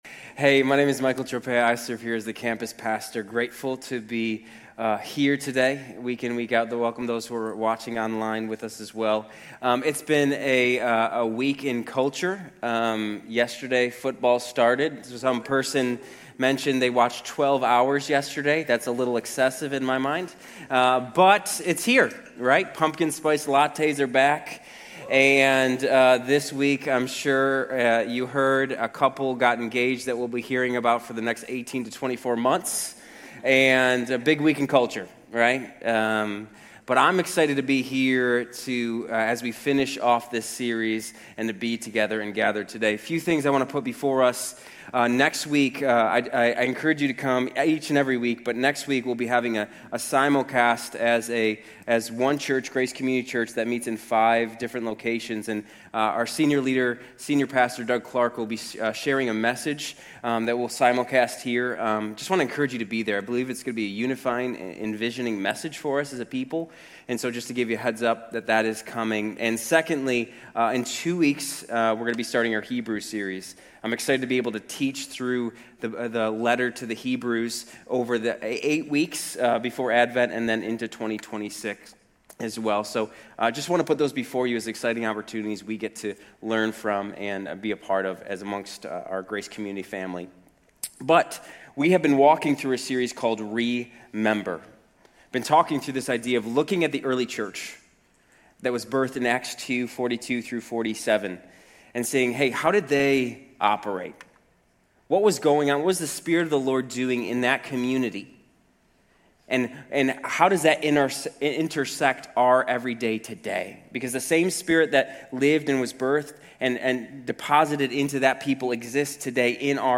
Grace Community Church University Blvd Campus Sermons 8_31 University Blvd Campus Aug 31 2025 | 00:36:27 1x 00:00 / 00:36:27 Subscribe Share RSS Feed Share Link Embed